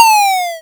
lose1.wav